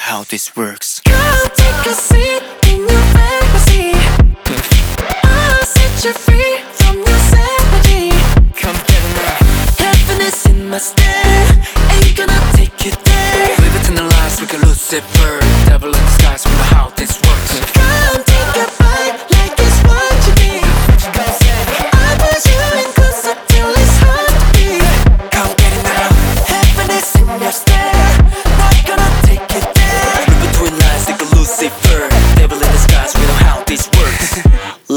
K-Pop Pop
Жанр: Поп музыка